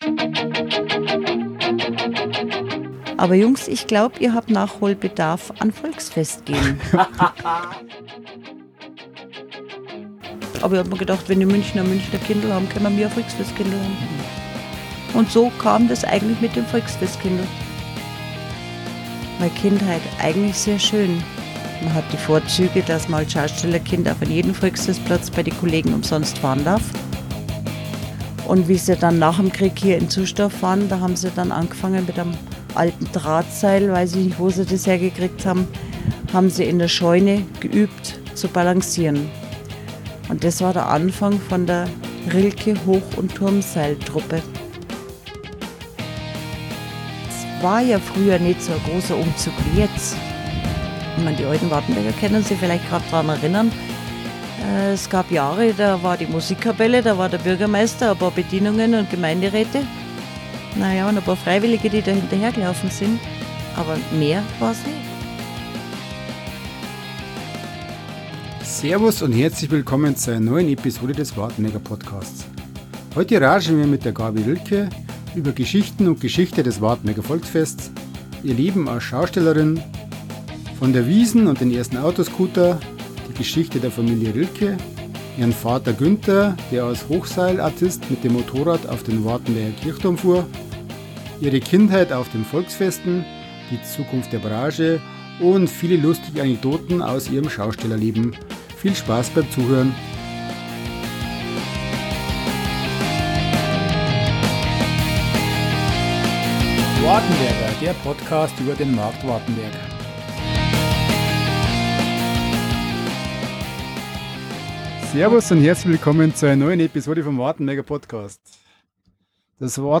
Passend zum „Voiksfest“ ein netter Ratsch aus ihrer zweiten Heimat – dem Wohnwagen auf dem Volksfestplatz …